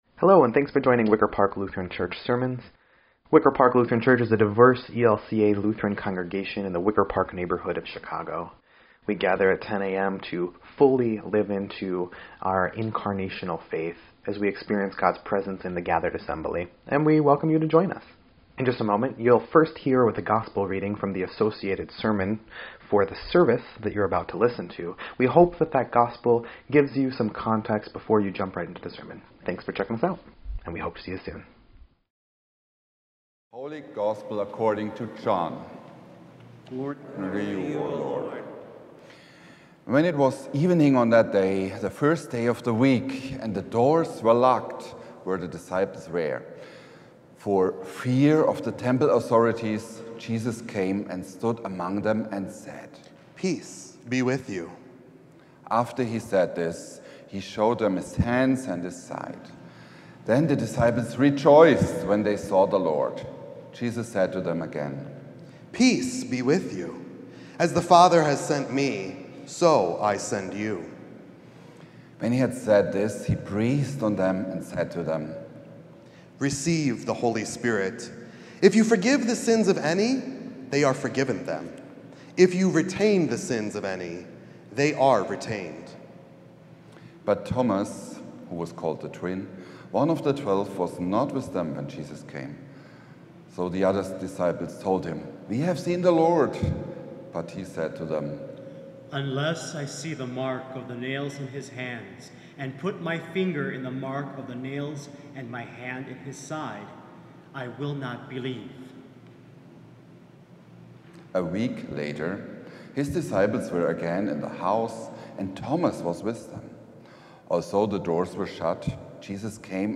4.27.25-Sermon_EDIT.mp3